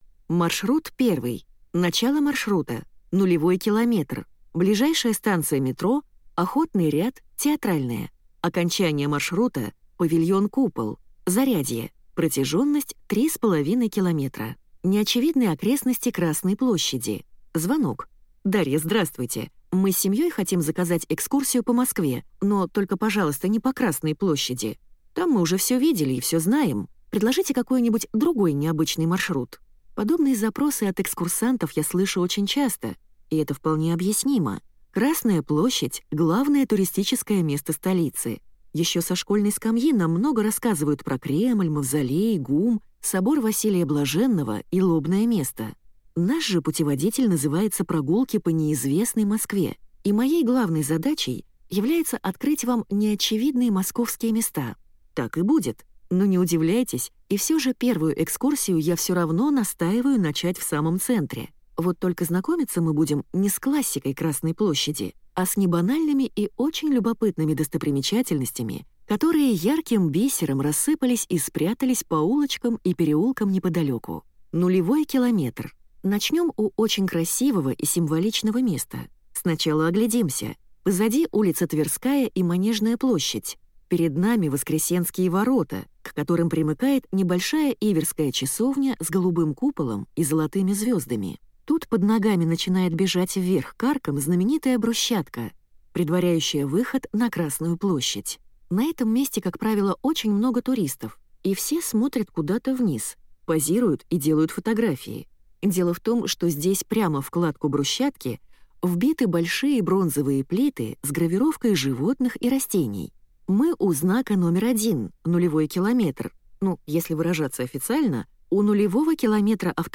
Аудиокнига Прогулки по неизвестной Москве | Библиотека аудиокниг